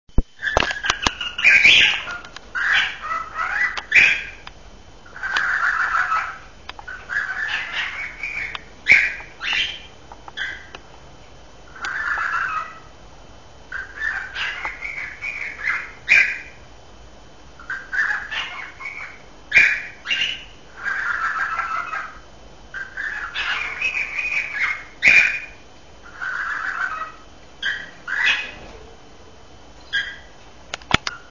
Balzende Hähne
Nymphensittichhähne können sehr unterschiedliche Gesänge entwickeln.
singenderhahn1.wav